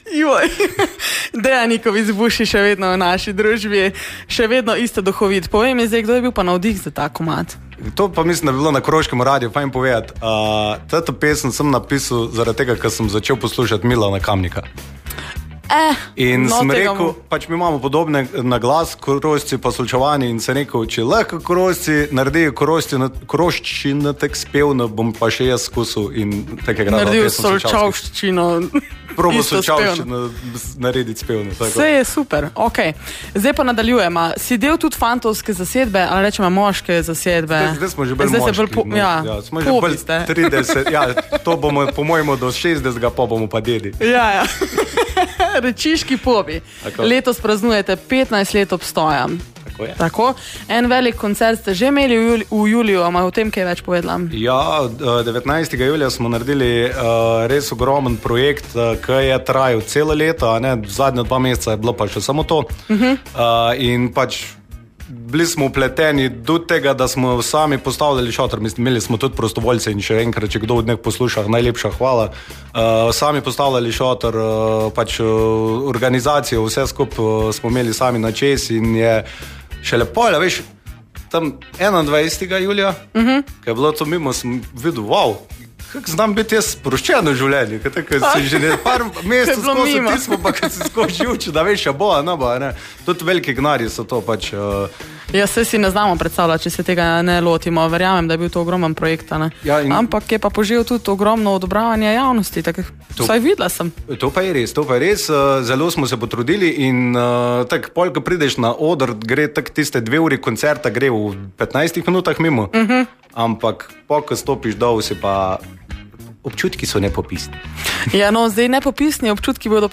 2. del pogovora